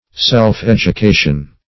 self-education.mp3